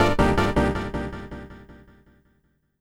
12SFX 01  -R.wav